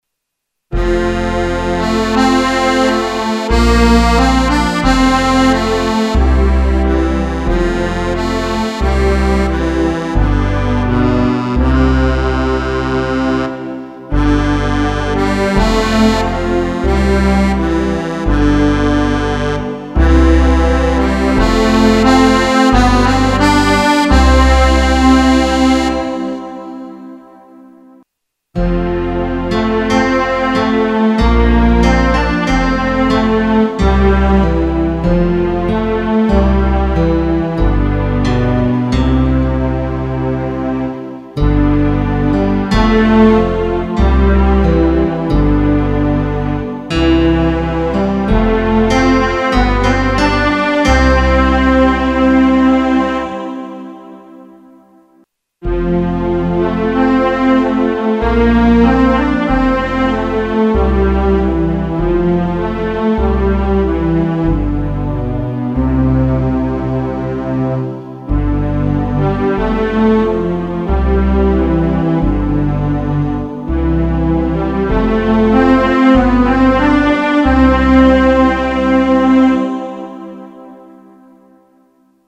Keyboard, live gespielt Version B; C-Dur